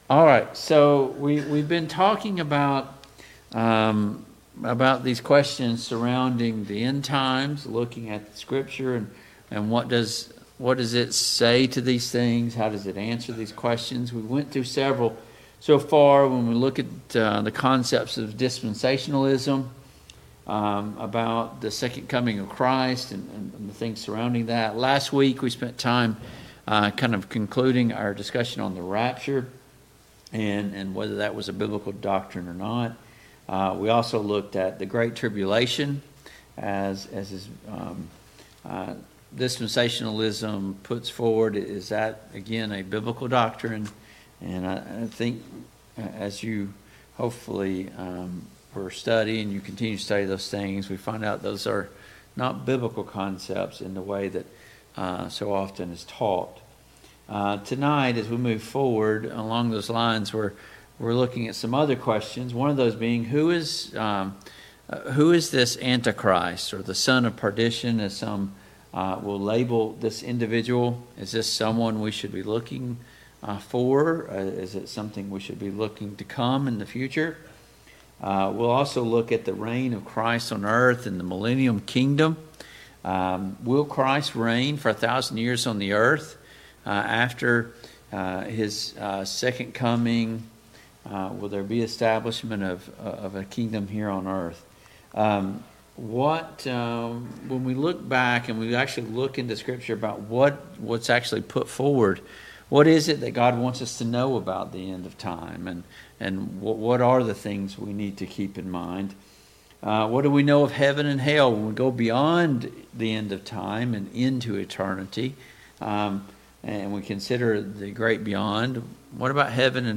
Service Type: Mid-Week Bible Study Download Files Notes Topics: The Anti-Christ , The Lawless One , The Son of Destruction « 1.